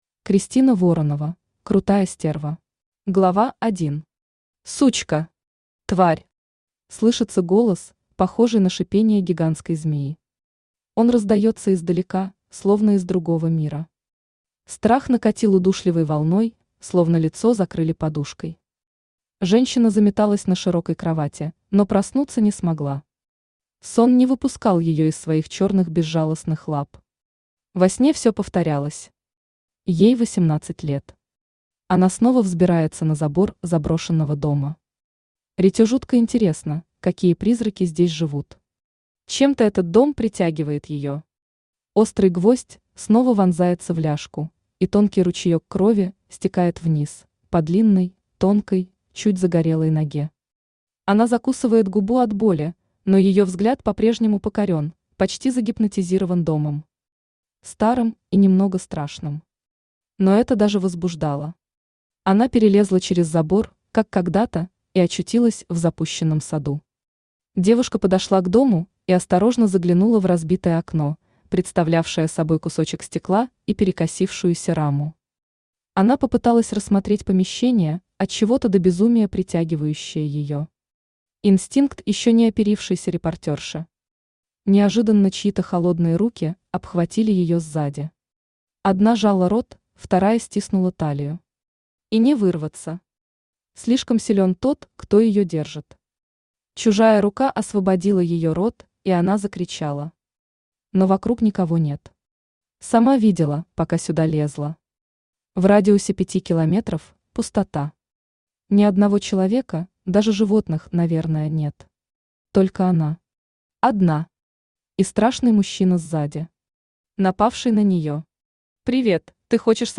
Аудиокнига Крутая стерва | Библиотека аудиокниг
Aудиокнига Крутая стерва Автор Кристина Воронова Читает аудиокнигу Авточтец ЛитРес.